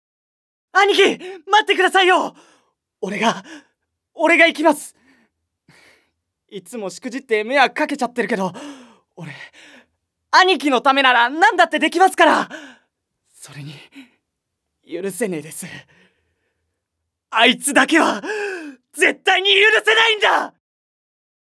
●ボイスサンプル１